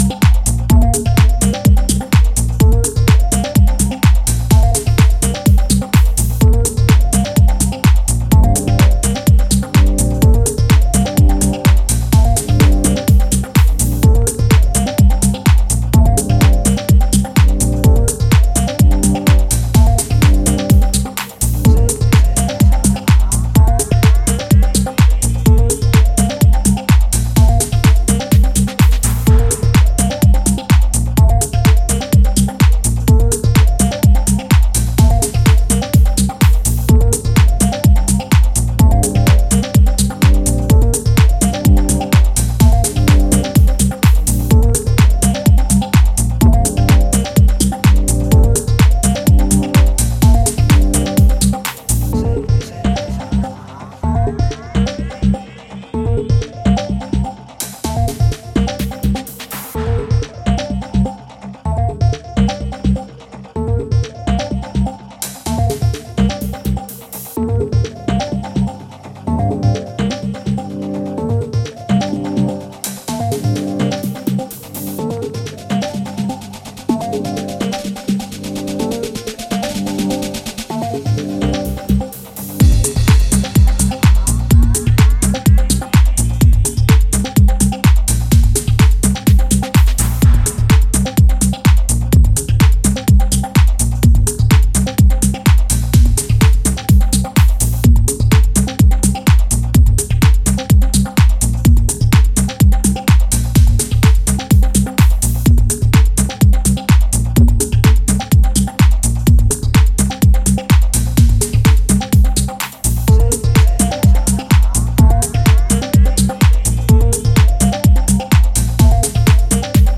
futuristic deep tech sounds with lively bass